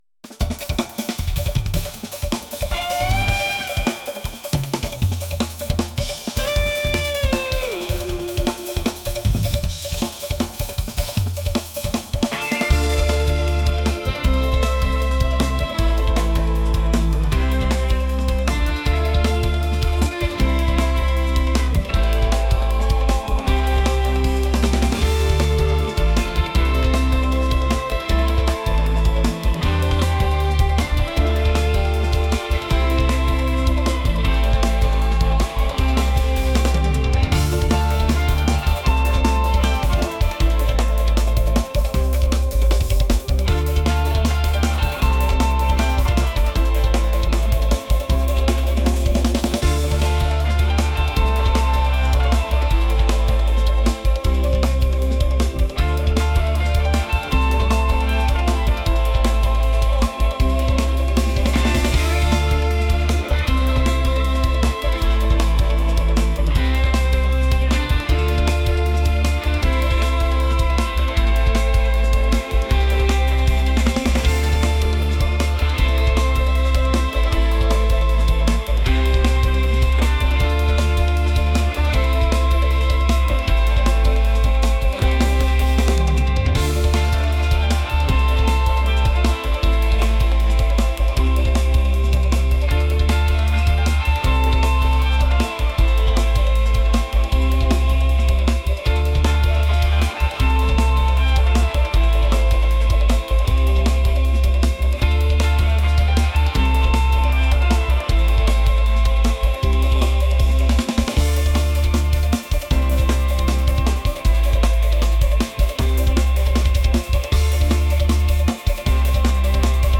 world | fusion | energetic